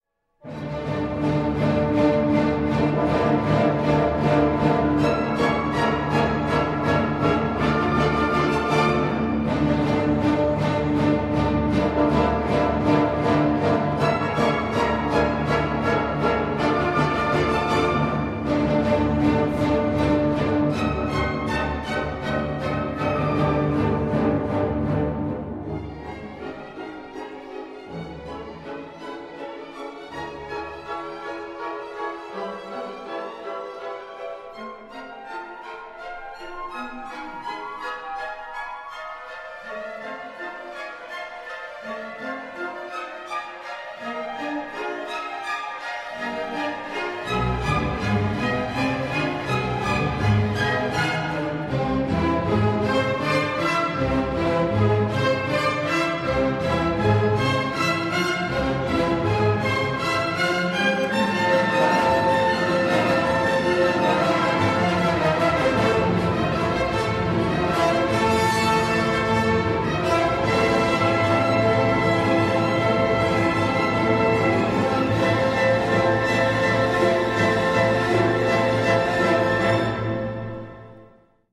Anton-Bruckner_-_Sinfonie-Nr_9_d-Moll.mp3